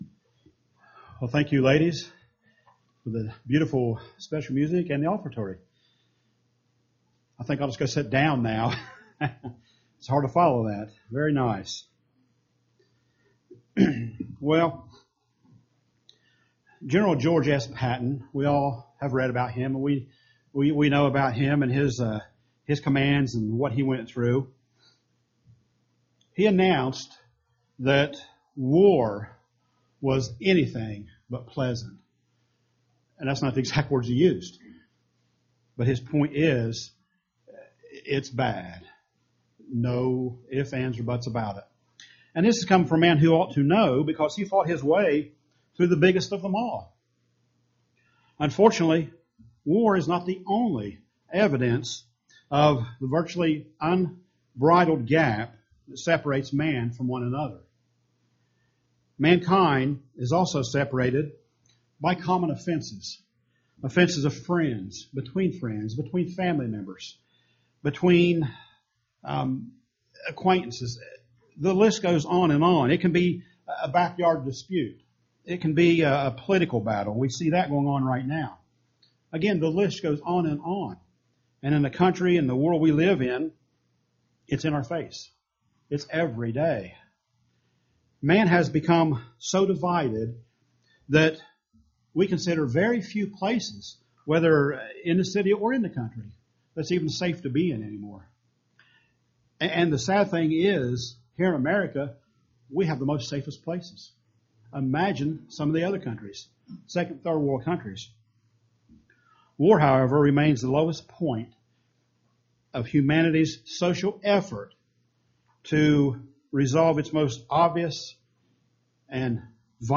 Webcast Sermons